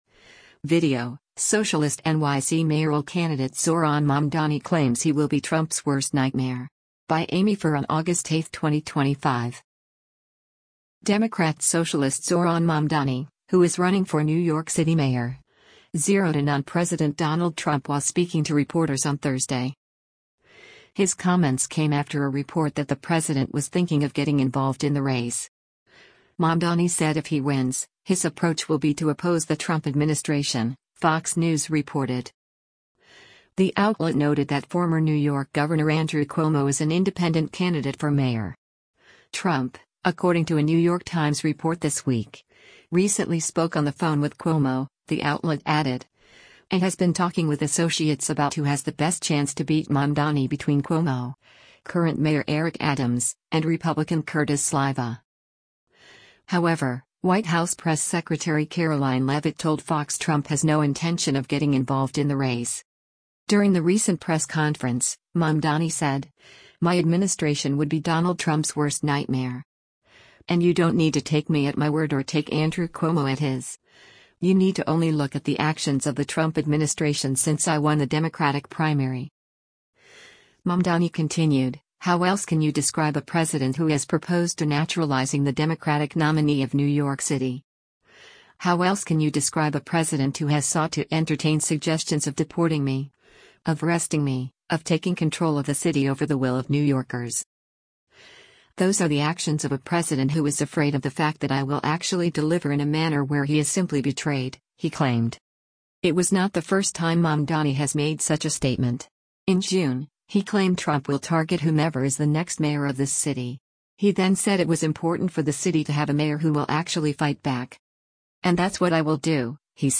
Zohran Mamdani, New York City mayoral candidate, during a news conference outside the Jaco
Democrat Socialist Zohran Mamdani, who is running for New York City mayor, zeroed in on President Donald Trump while speaking to reporters on Thursday.